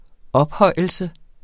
Udtale [ ˈʌbˌhʌjˀəlsə ]